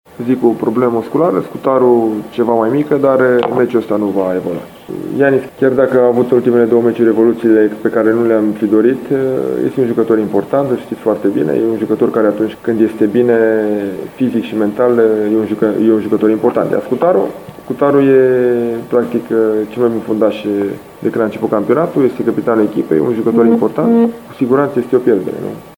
Antrenorul polist Dan Alexa a vorbit despre absenţa celor doi.